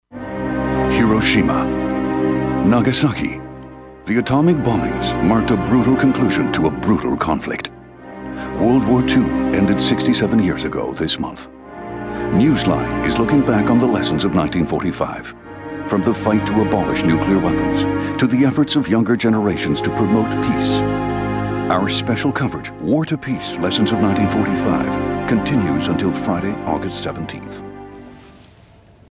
English inflection: Neutral North American
Tone: Baritone